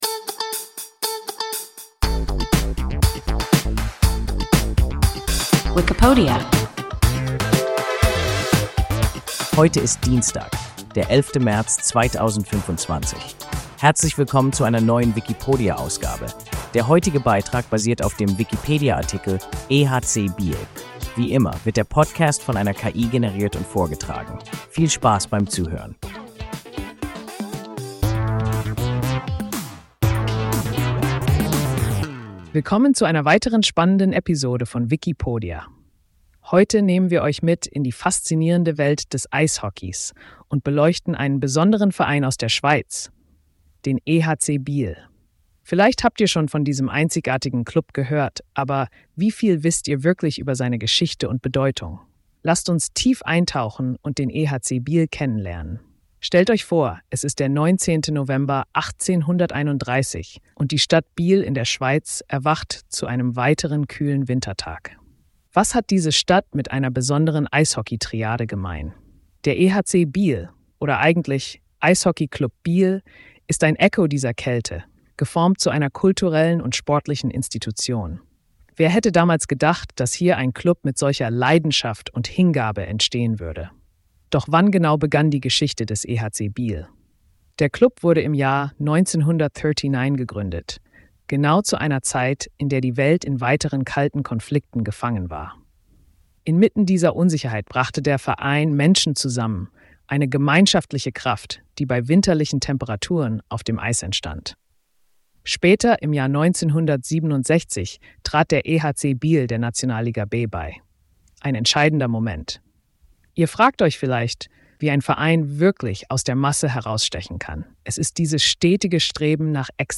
EHC Biel – WIKIPODIA – ein KI Podcast